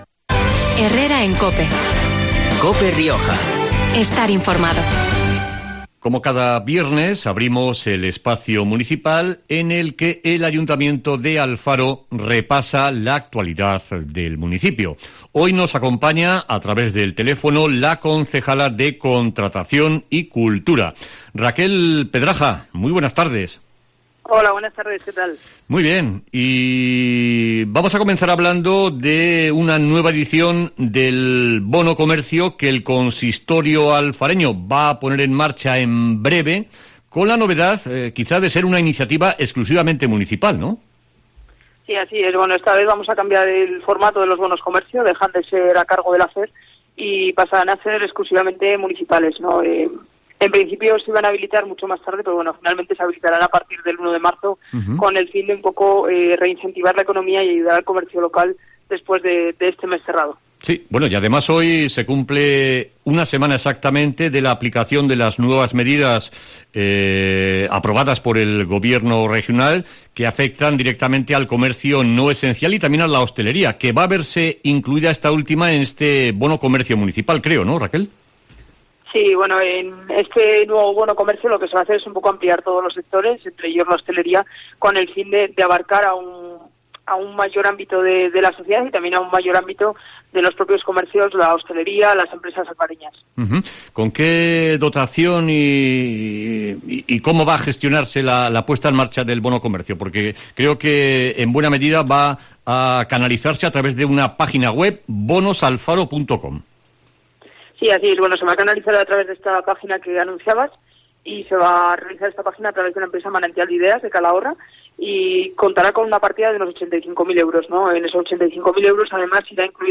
Entrevista en COPE Rioja a Raquel Pedraja, concejala de Contratación de Alfaro